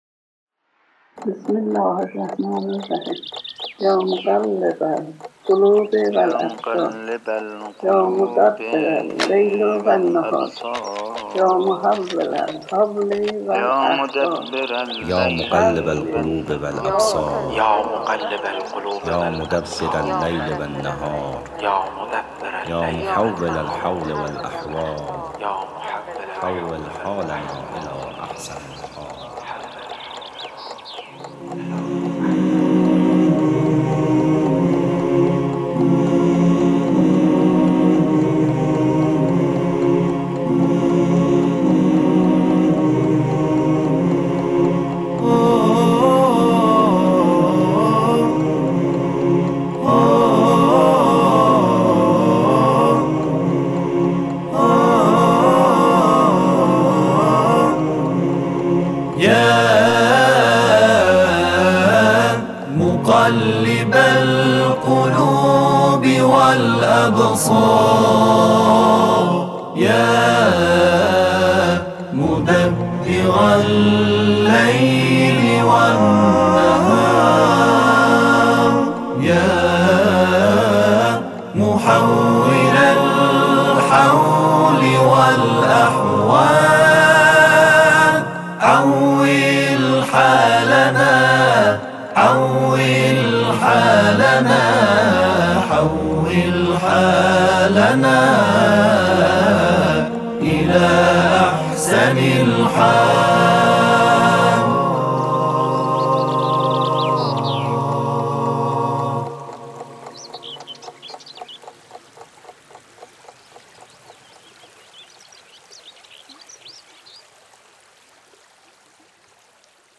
به مناسبت حلول سال 1399 خورشیدی، دعای «مقلب القلوب و الابصار» را با نوای گروه تواشیح و همخوانی معارج تبریز می شنوید.